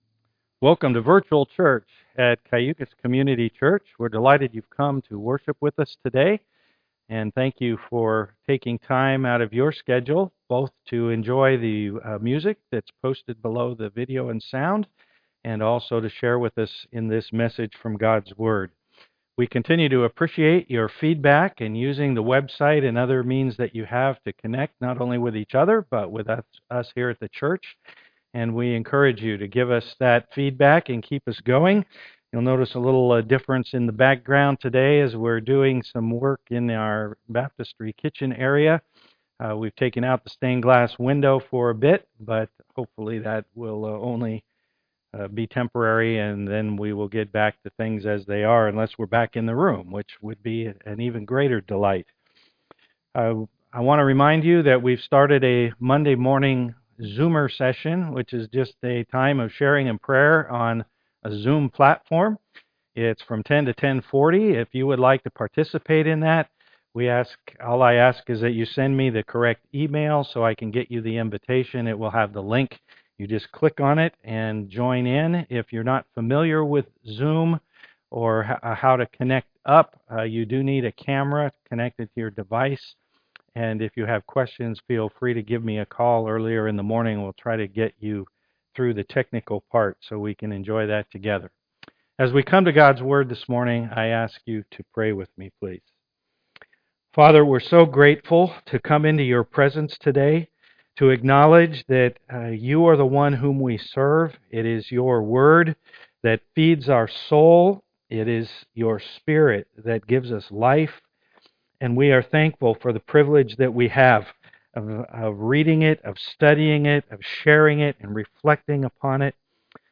Series: Walking the (COVID-19) Wilderness With Moses Passage: Exodus 1:1-3:1 Service Type: am worship